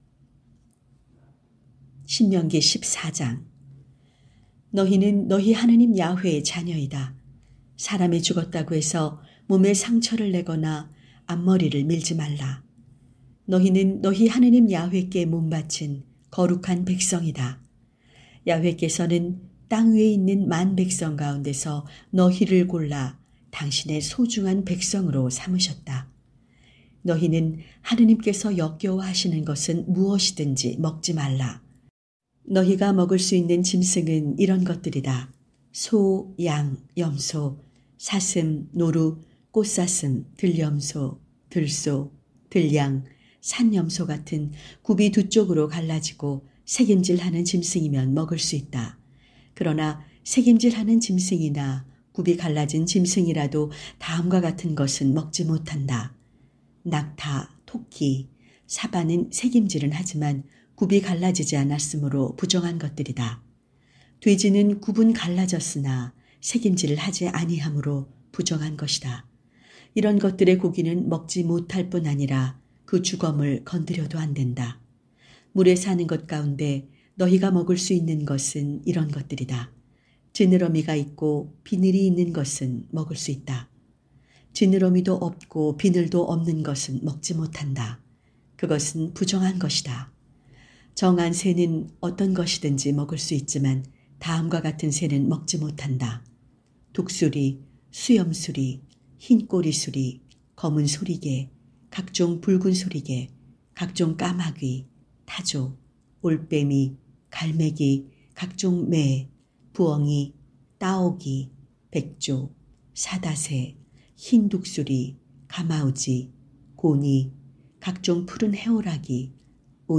성경 오디오